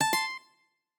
lute_ac1.ogg